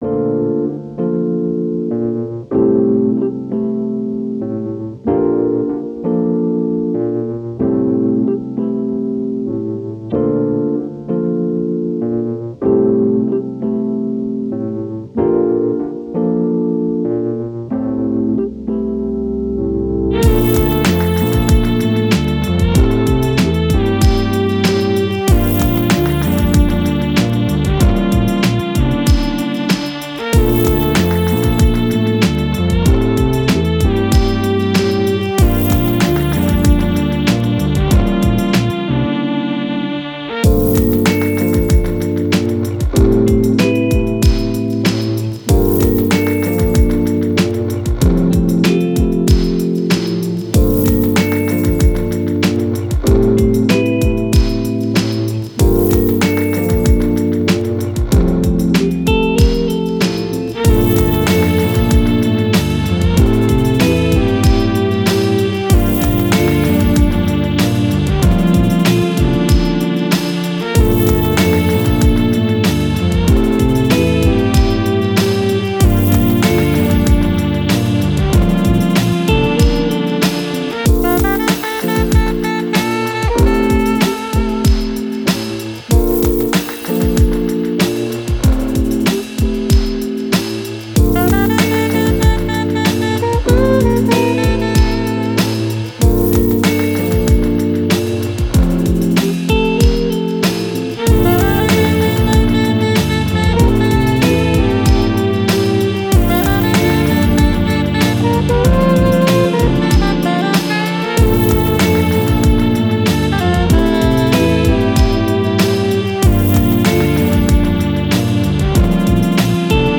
Downtempo, Trip Hop, Jazz, Chill, Cool, City